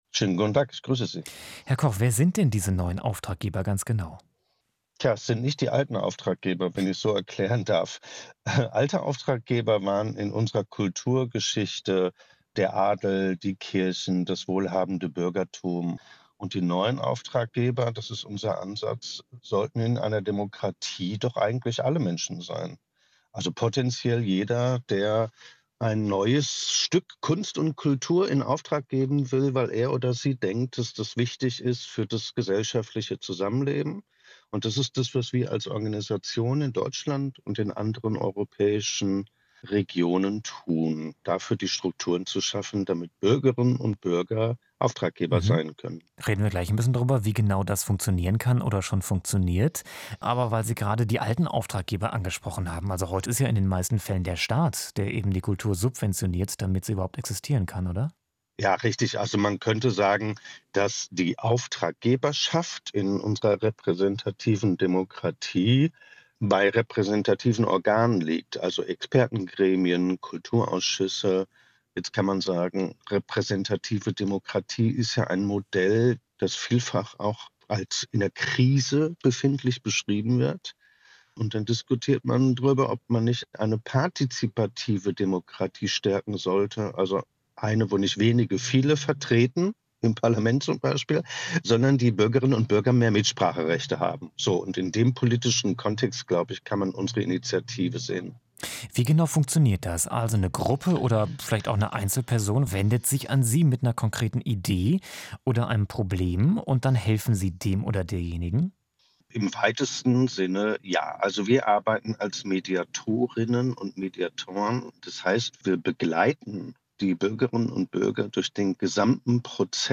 Bürger beauftragen Kunst zum Wohl der Allgemeinheit: Interview